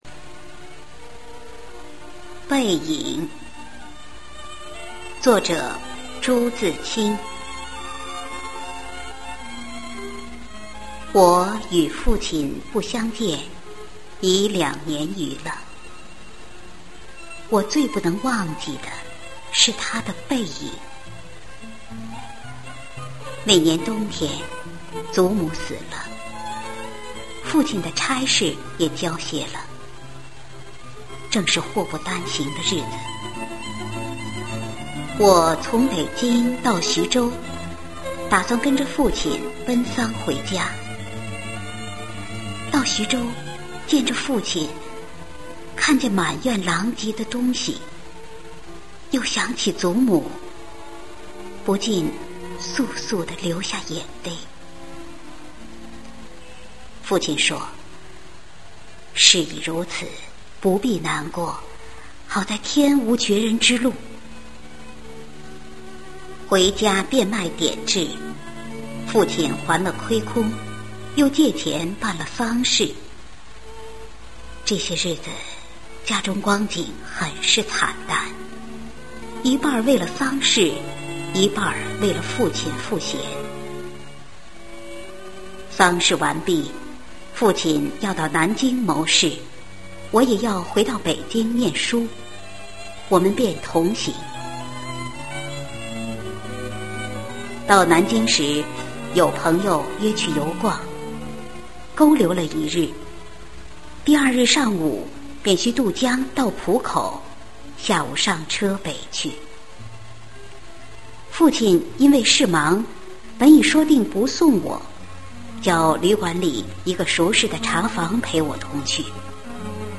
《背影》女声朗读